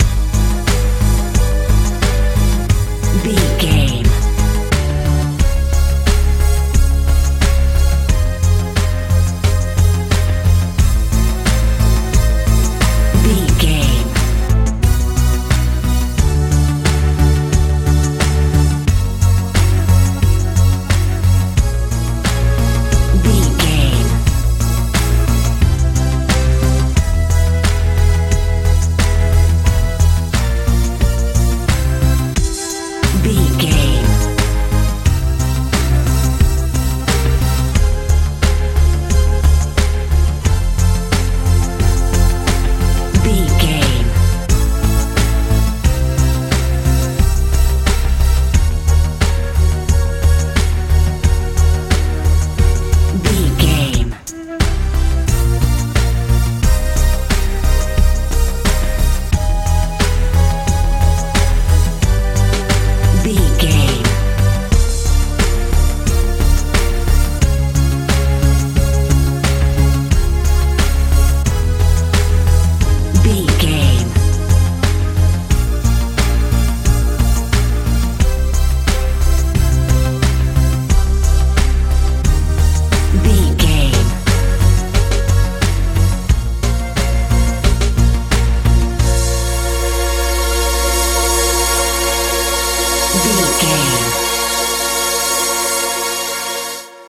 pop dance
Locrian
A♭
magical
powerful
synthesiser
bass guitar
drums
strange
suspense
tension
mystical
ethereal